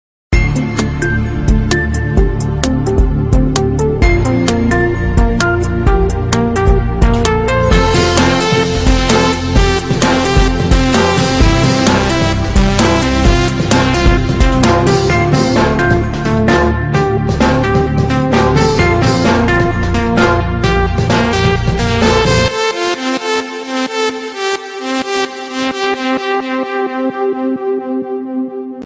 Стандартные рингтоны